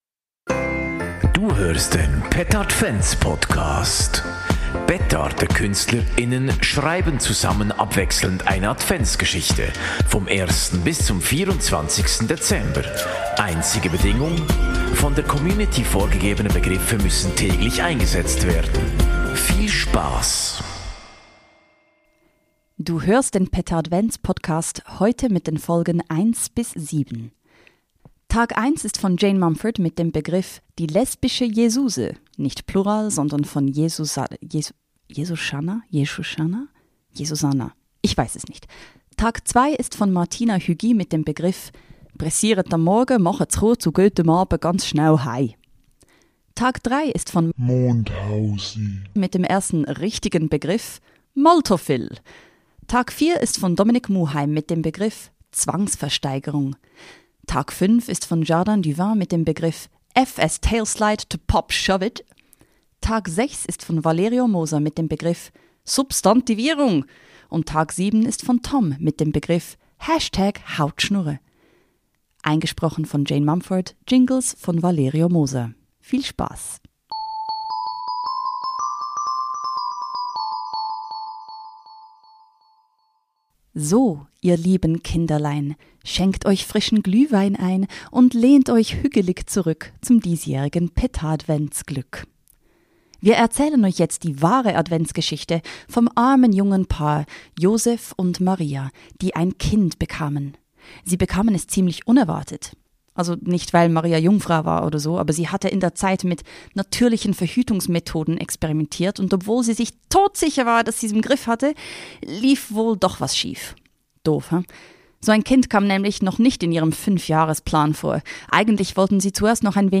Der Petardvent Podcast ist ein Adventspodcast der Satiremarke Petarde. Petardekünstler:innen schreiben abwechselnd eine Adventsgeschichte vom ersten bis zum 24. Dezember. Einzige Bedingung: Die von der Community vorgegebenen Begriffe müssen täglich in die Geschichte eingebaut werden.